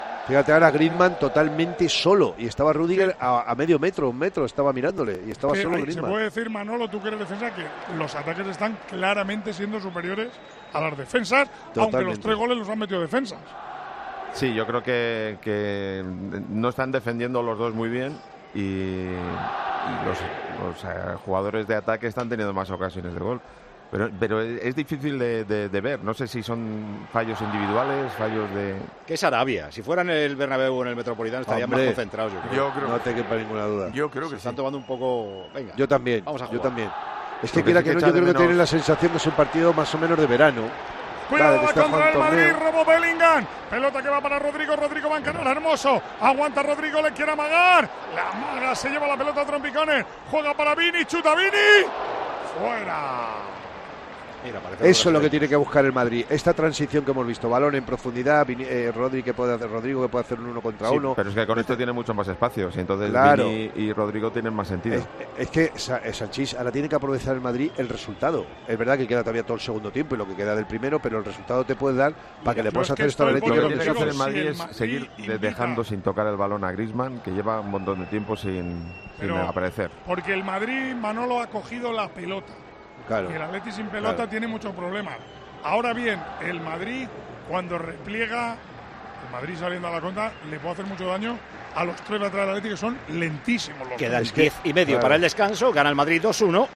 ESCUCHA A MANOLO SANCHÍS Y A POLI RINCÓN EN TIEMPO DE JUEGO, DURANTE EL REAL MADRID - ATLÉTICO DE MADRID